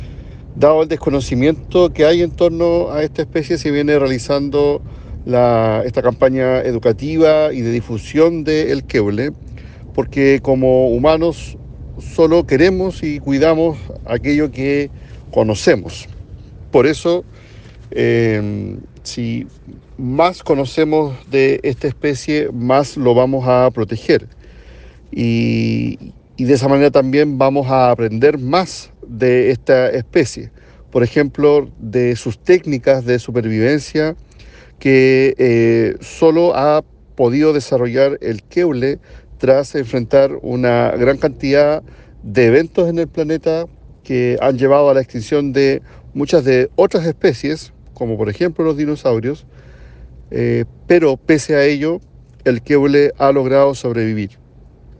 cuna-2-seremi-oscar-reicher.mp3